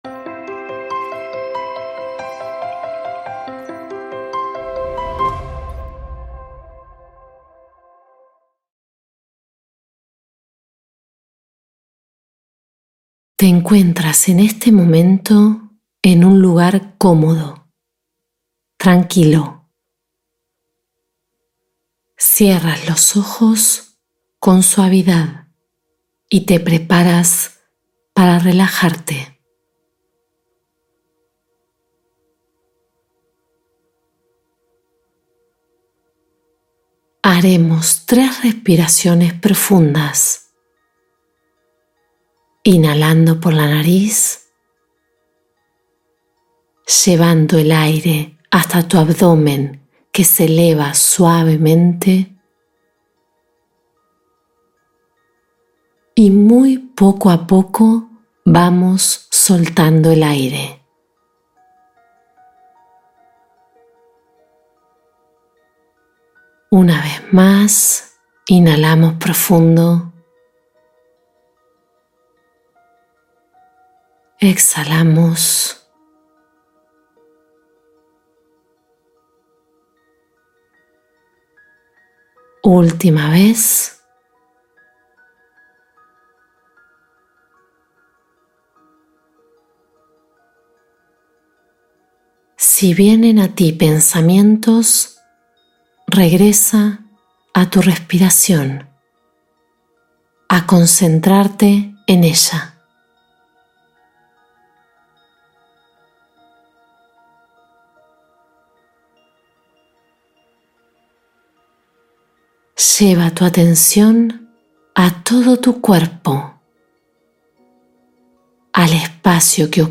Paz Interior: Meditación Corta Para Alcanzar Serenidad